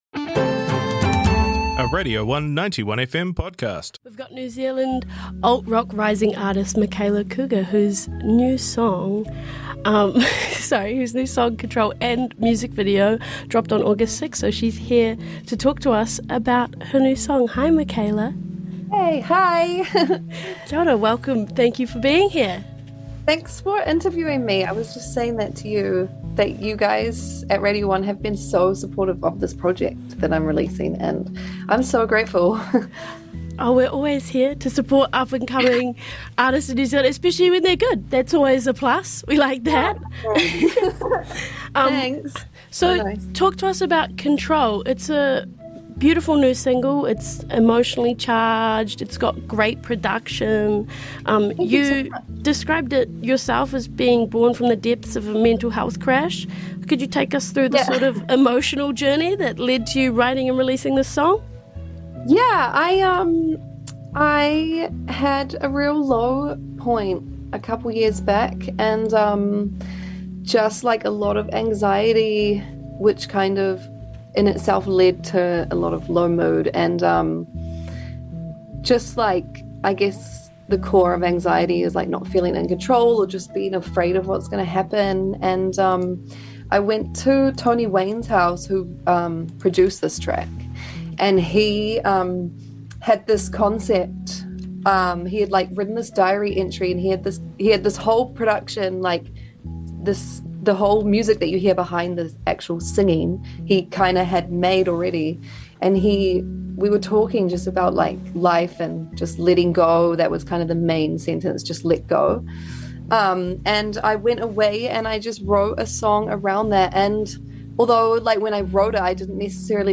Podcasts, archived interviews and Live To Airs